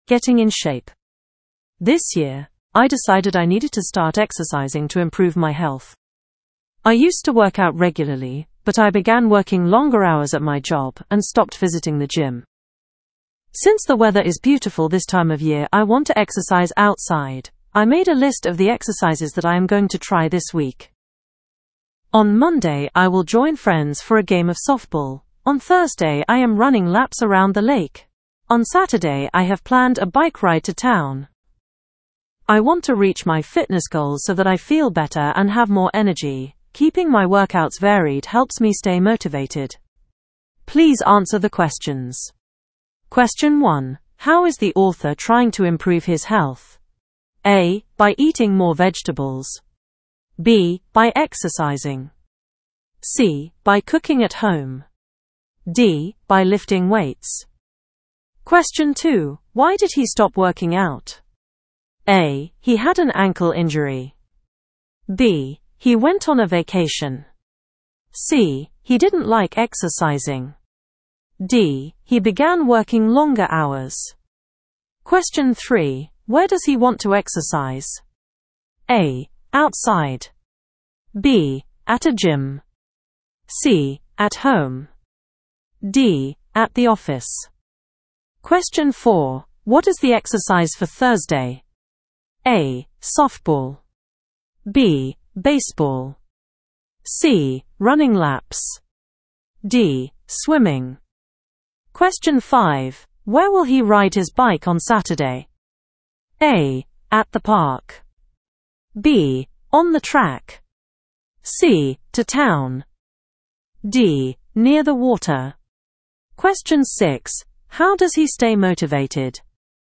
Inglaterra